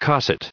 949_cosset.ogg